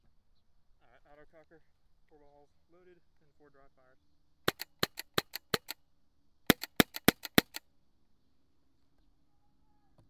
autococker_raw_-3db_01.wav